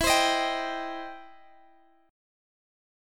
Listen to EmM7#5 strummed